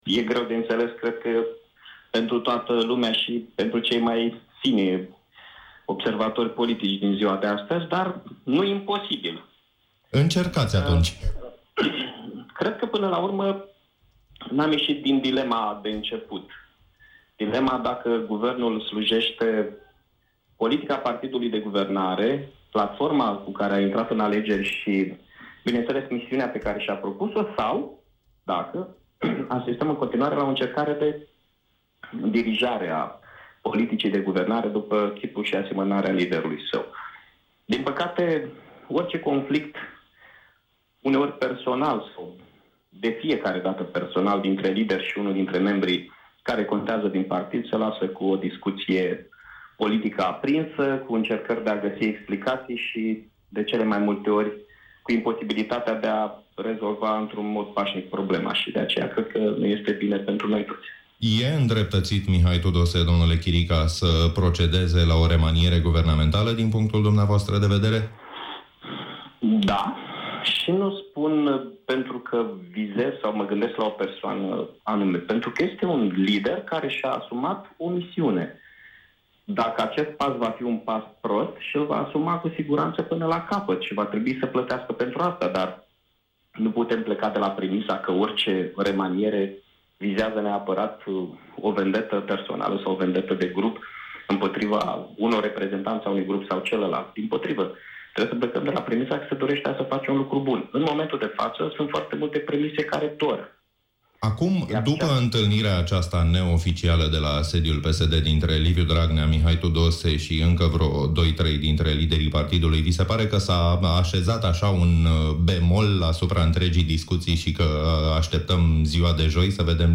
Primarul Iașului, Mihai Chirica, invitat la emisiunea Drum cu prioritate a atras atenția că actualul conflict dintre premierul Mihai Tudose și președintele PSD, Liviu Dragnea, nu este relevant pentru populație.